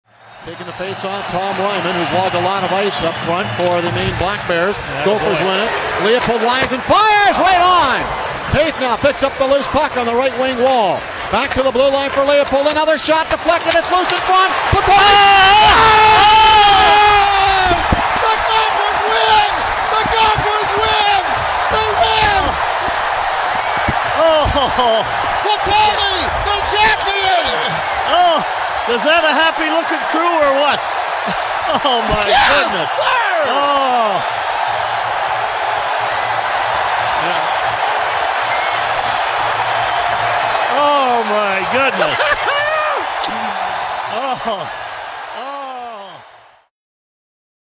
voice as the Gophers won a national title on Xcel Energy Center ice in 2002.